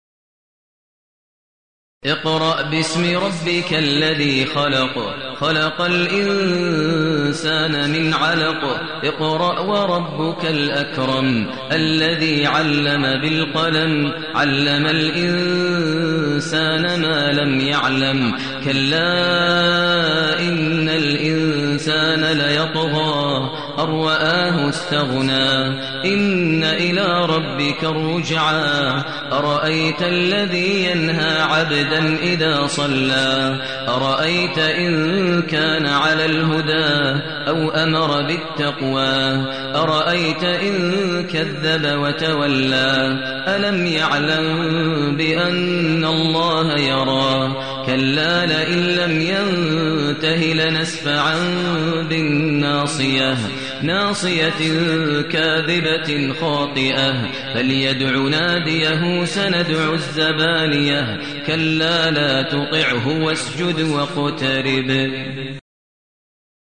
المكان: المسجد الحرام الشيخ: فضيلة الشيخ ماهر المعيقلي فضيلة الشيخ ماهر المعيقلي العلق